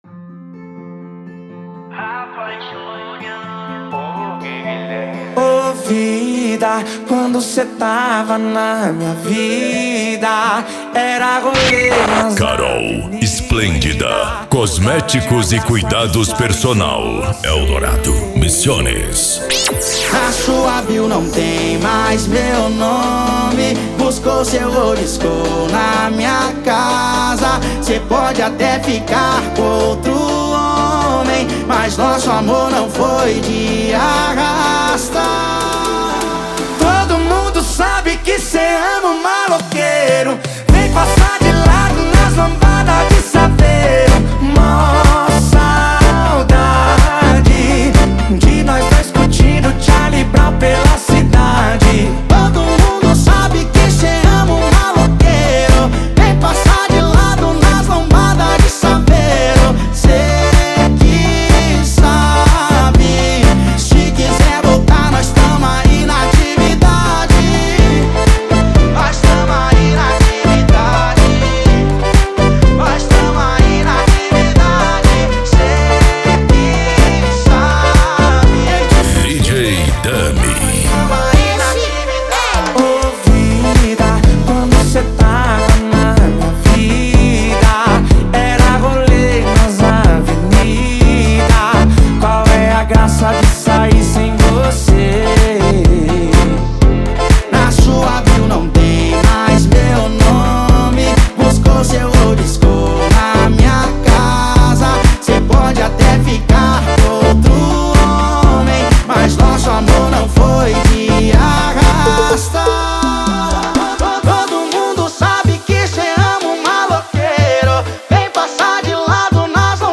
Arrocha
Funk
Reggaeton